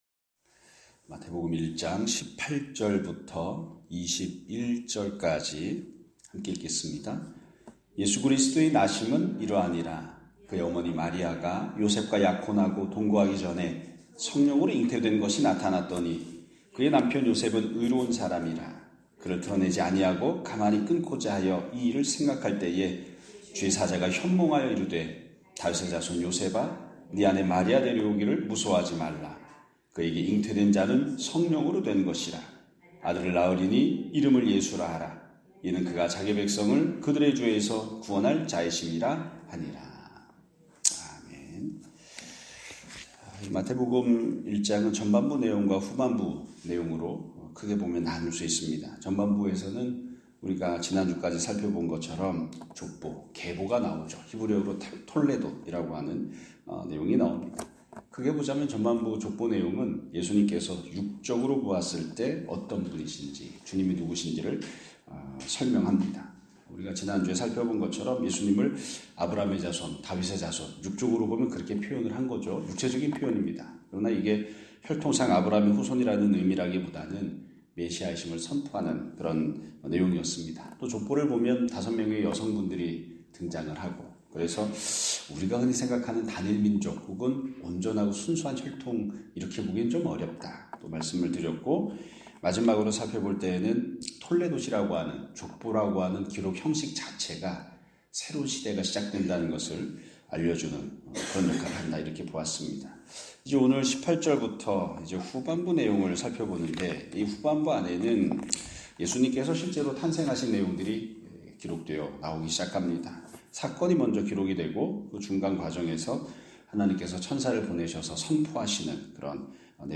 2025년 3월 24일(월요일) <아침예배> 설교입니다.